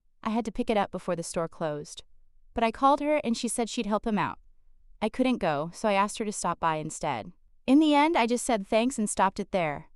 使うのは、日常会話のよくあるワンシーンです。
kとi、tとuがくっついて、「ピキラッ（プ）」のように一つの単語みたいに聞こえます。
h の音が消えて（リダクション）、d と e がくっつくため、”Called her” は「コルダー」になります。
「ストップ」＋「イット」ではなく、「ストップティ」と聞こえます。